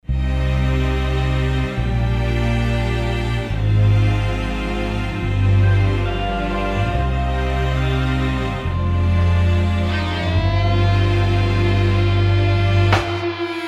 Интересный саунд стринг пэда
Очень нравятся струнные " подьезды" при смене аккордов.